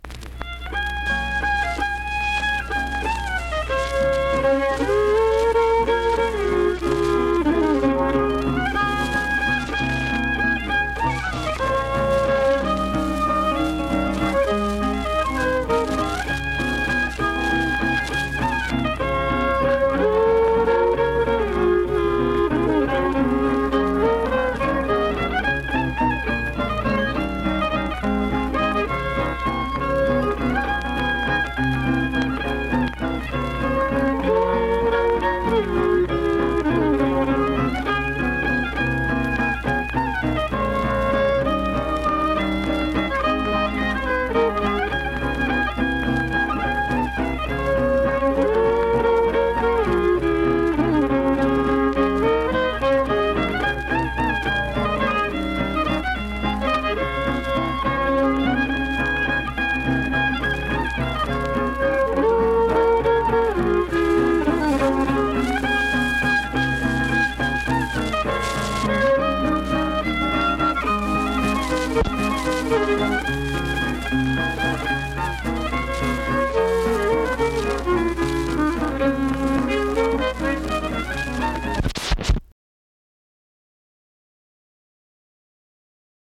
Instrumental performance with fiddle and guitar.
Instrumental Music
Guitar, Fiddle
Vienna (W. Va.), Wood County (W. Va.)